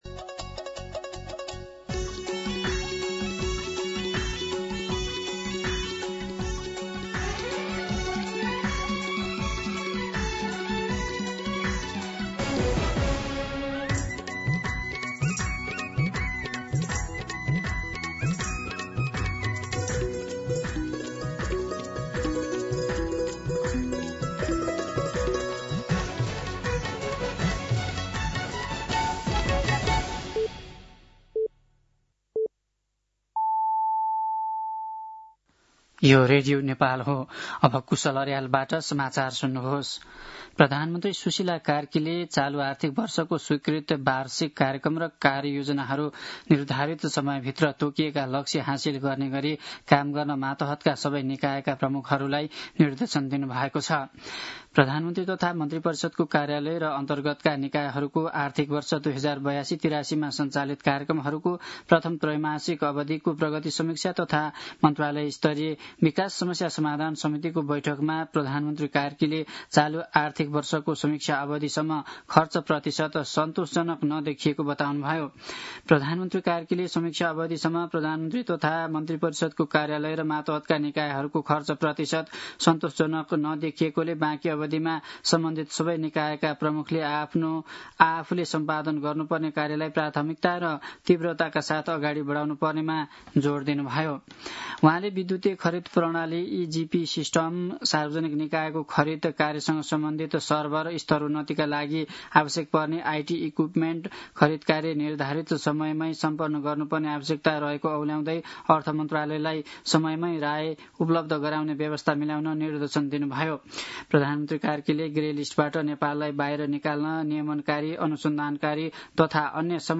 साँझ ५ बजेको नेपाली समाचार : २७ कार्तिक , २०८२
5pm-news-7-27.mp3